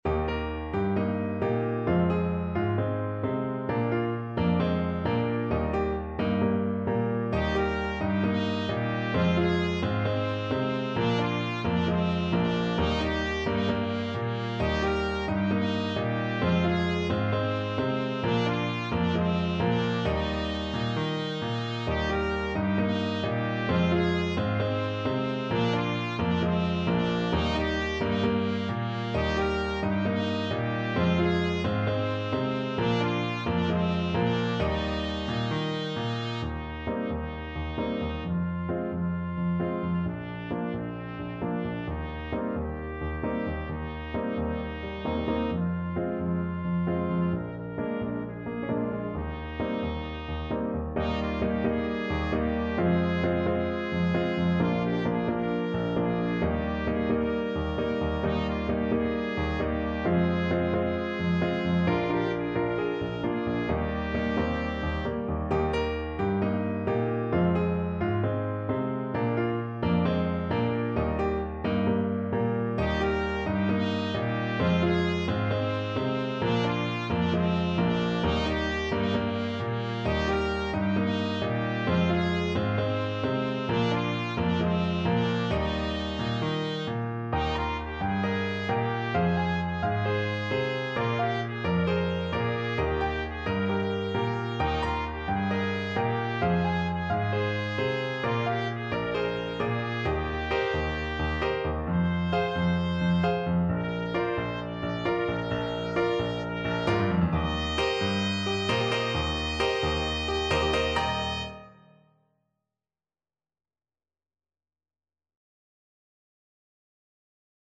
Trumpet version
Calypso =c.132
4/4 (View more 4/4 Music)
Traditional (View more Traditional Trumpet Music)